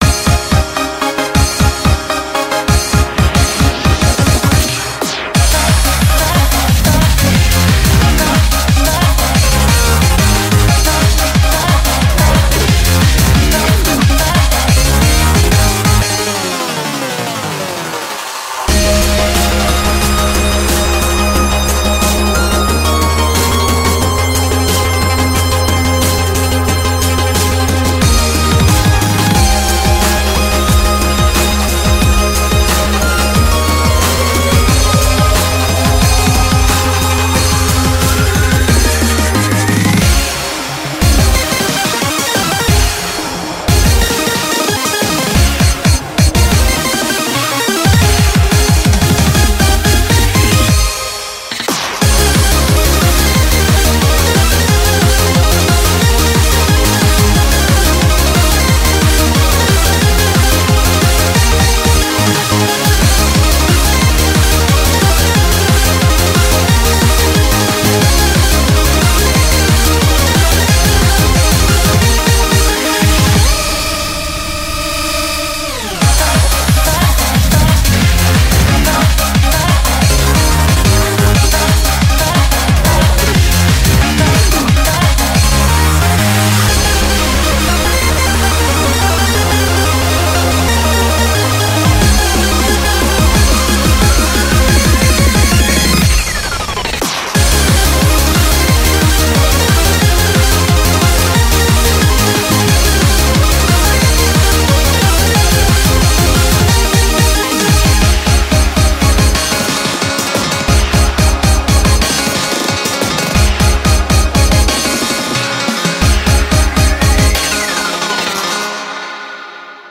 BPM180
Audio QualityMusic Cut